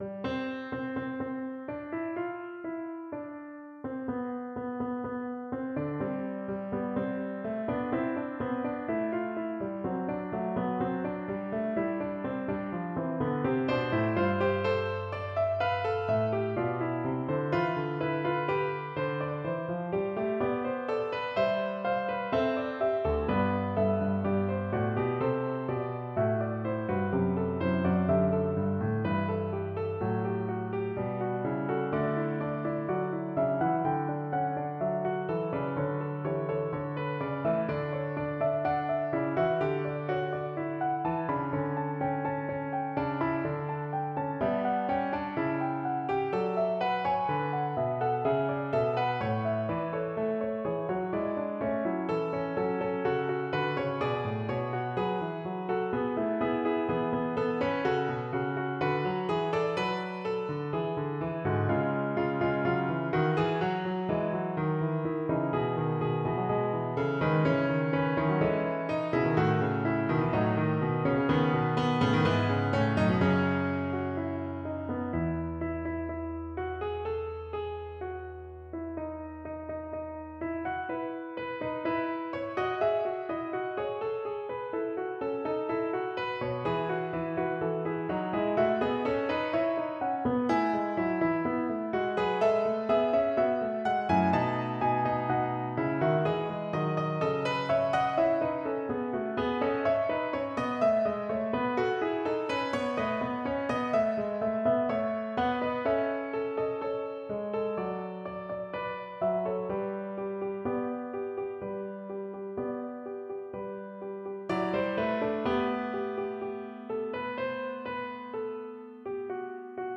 Here are a few (headphones are recommended, I’m no sound engineer and it sounds like shit on laptop speakers):
On a tune from a children’s summer song, for piano.
idafugue.mp3